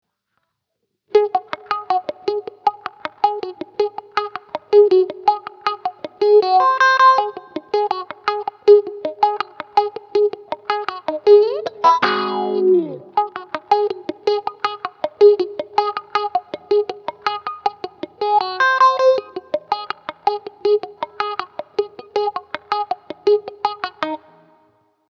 neo soul